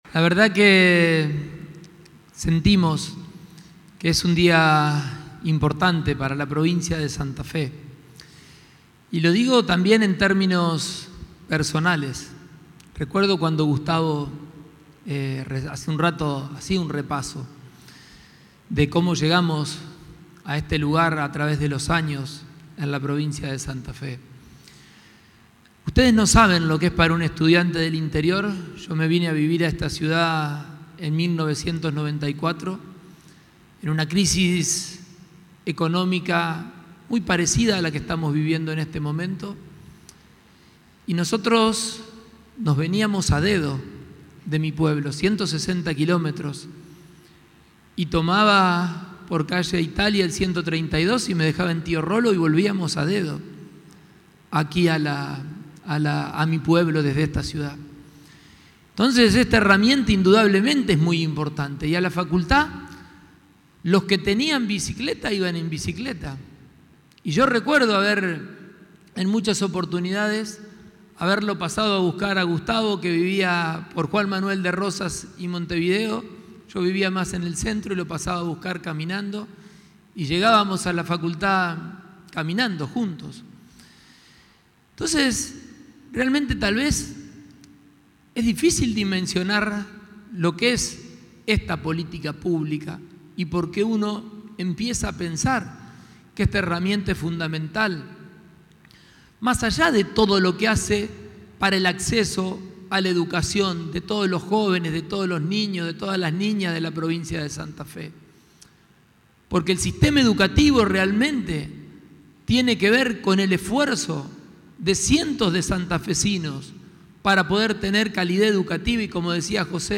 Esta mañana, en la sede de Gobierno de Rosario, se presentó la nueva edición de Boleto Educativo 2025 (BE), que incorpora novedades: más presencia territorial en municipios y comunas y orientación presencial a partir de turnos otorgados previamente online.
Gobernador Maximiliano Pullaro